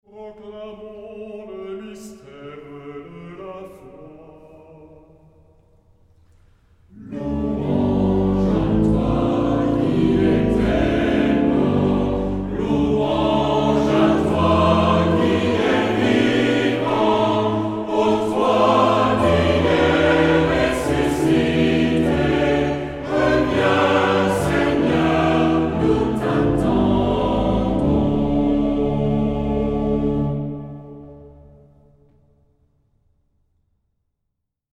Genre-Style-Form: Acclamation
Mood of the piece: adoring
Type of Choir: SATB  (4 mixed voices )
Instrumentation: Organ  (1 instrumental part(s))
Tonality: D modal